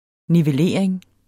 Udtale [ nivəˈleˀɐ̯eŋ ]